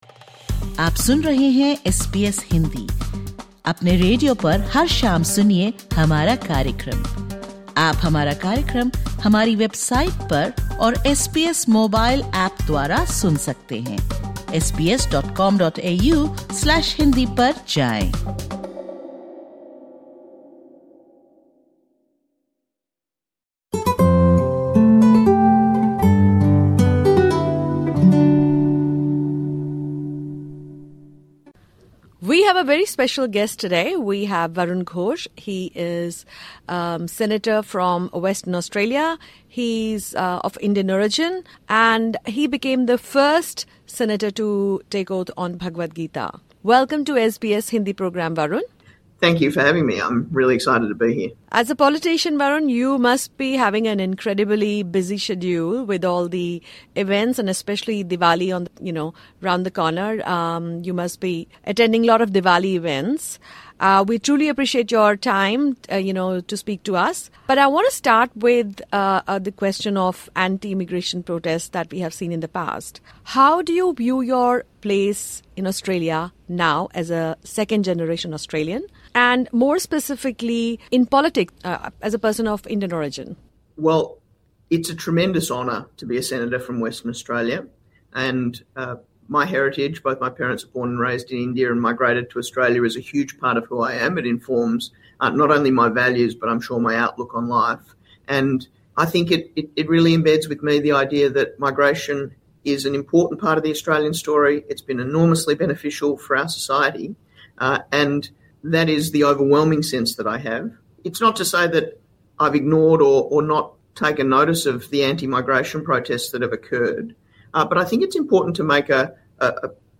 Listen to this podcast with Varun Ghosh, a Labor Senator from Western Australia and the first Australian Senator to take his oath on the Bhagavad Gita. While affirming his identity as an Australian, he reflects on how his Indian heritage has shaped his life. Senator Ghosh, who was also part of the Australia India Youth Dialogue (AIYD), shares his views on Diwali being recognised as a public holiday in Australia and highlights the growing involvement of the Indian community in Australian politics.